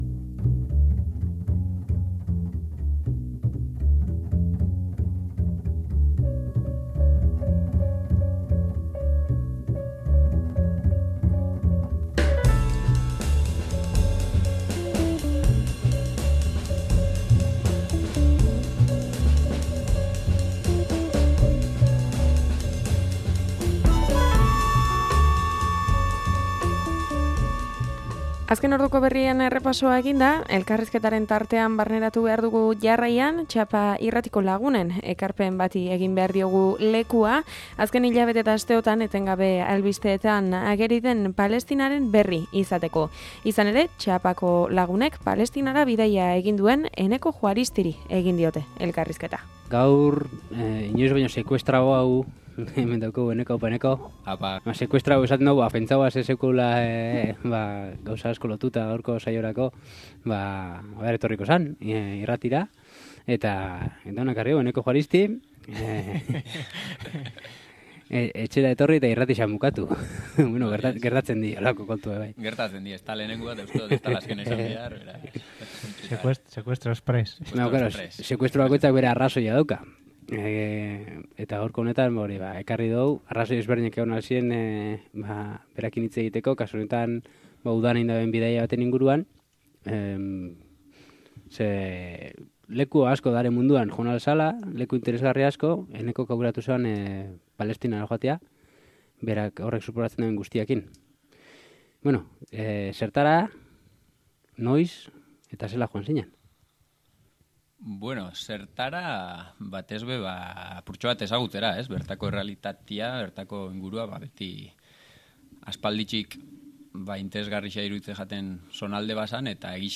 Txapa irratiko kideen ekarpenari egin diogu tartea gaurko eguneko elkarrizketan.